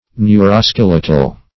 Search Result for " neuroskeletal" : The Collaborative International Dictionary of English v.0.48: Neuroskeletal \Neu`ro*skel"e*tal\, a. Of or pertaining to the neuroskeleton.
neuroskeletal.mp3